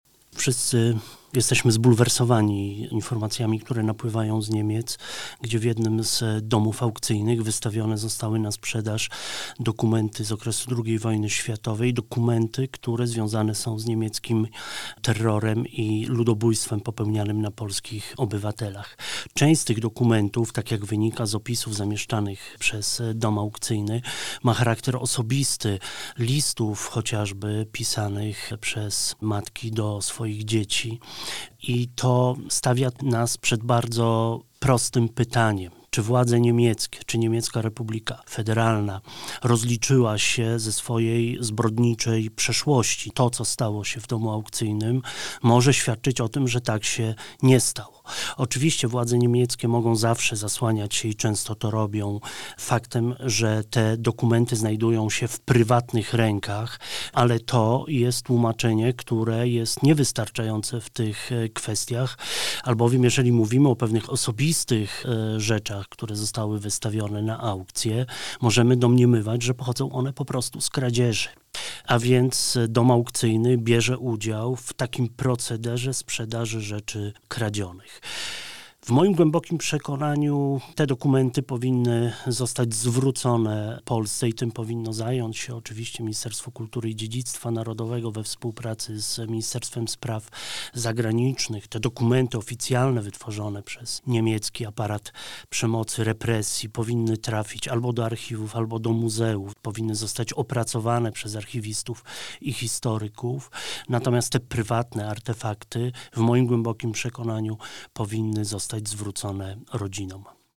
Centrala Komunikat logoIPN Pliki do pobrania Sprzeciw wobec handlu świadectwami zbrodni niemieckich z czasów II wojny światowej – wypowiedź zastępcy prezesa IPN dr. hab. Karola Polejowskiego (mp3, 2.54 MB)